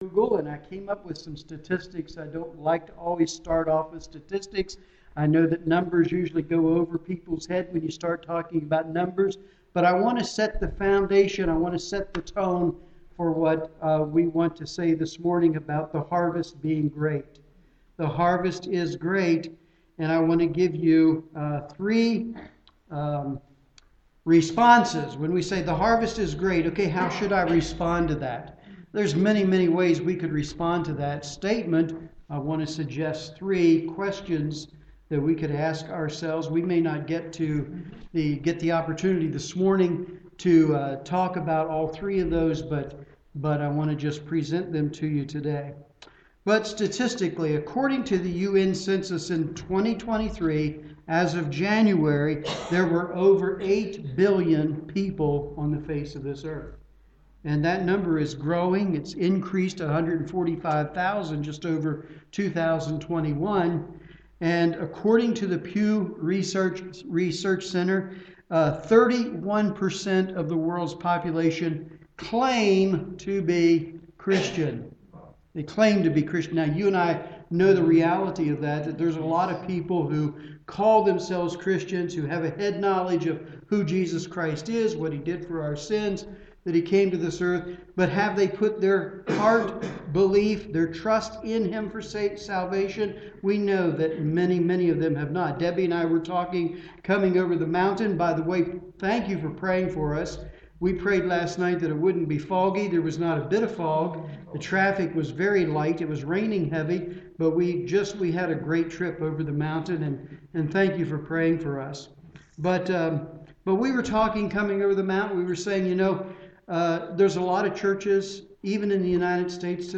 2023 Missions Conference
Sermon